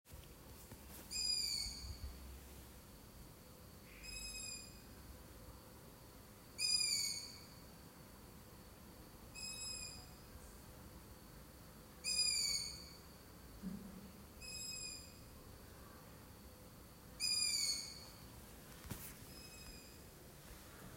Zdrav�m, p�est�hoval jsem se na s�dli�t� do bytu a ka�dou noc je sly�et tento zvuk v p��loze, kv�li kter�mu se ned� moc sp�t p�i otev�en�m okn�, proto�e je opravdu hlasit� (probl�m je, �e teplota v m�stnosti je u� te� v �ervnu p�es den 27�C, proto se sna��m to v noci srazit v�tr�n�m), bohu�el si asi budu muset zvyknout